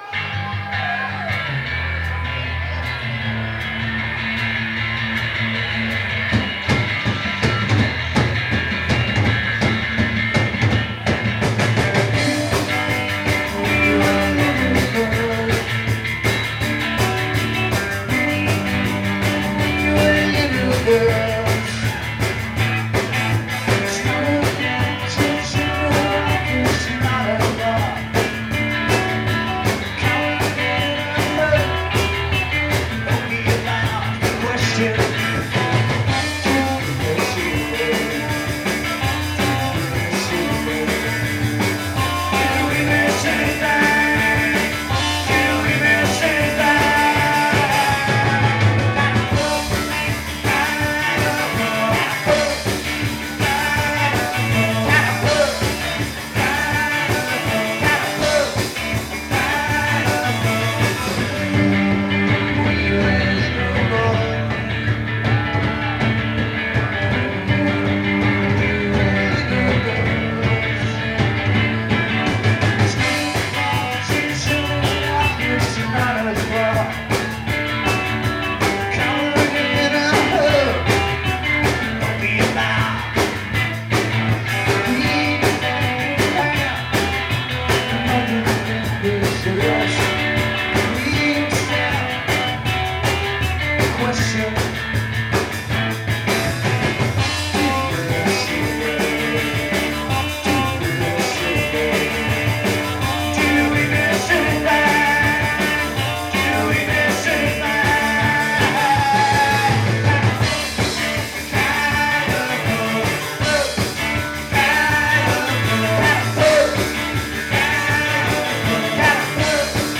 The Strand Cabaret, Marietta, GA